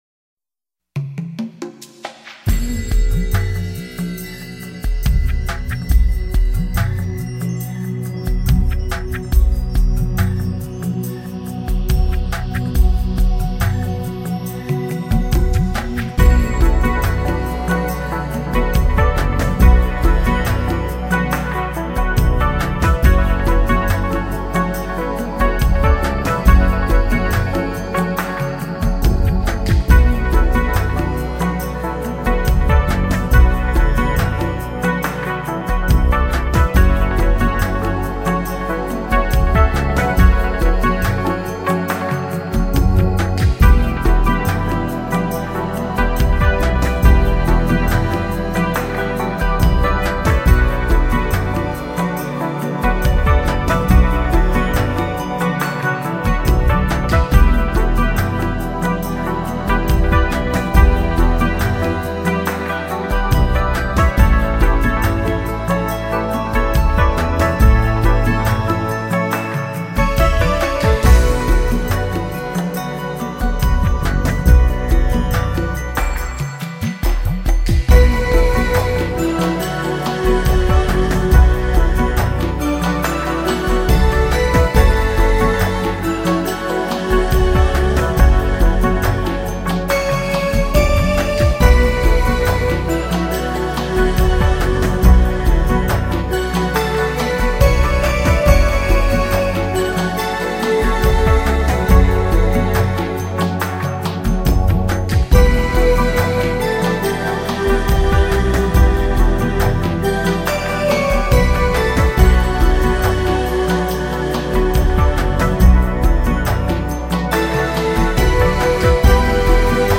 音乐类别：部落民间
带来了旋律特有的优美而梦幻般的感觉。
拥有了那种洒脱和亮丽的色彩。 音乐采用了熟悉的乐器，长笛，钢琴，电子键盘和打击乐器，而更有我们熟悉的竹笛的殷殷切切。